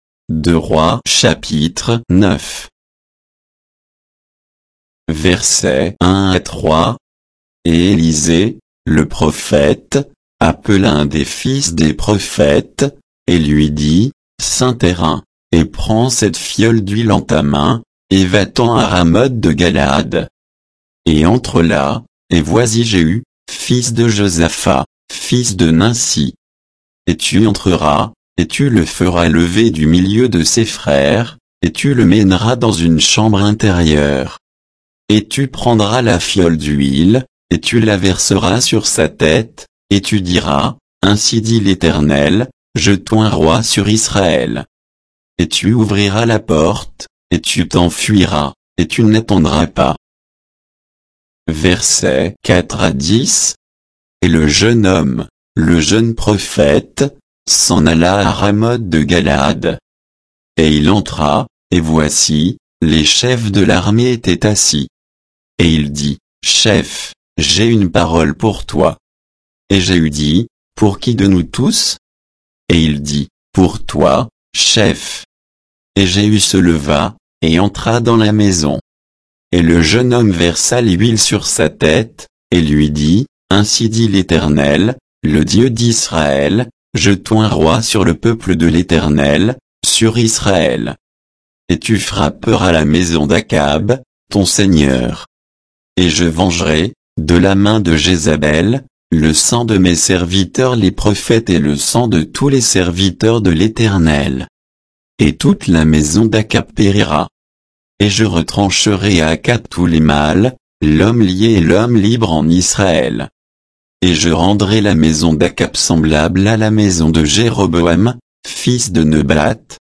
Bible_2_Rois_9_(avec_notes_et_indications_de_versets).mp3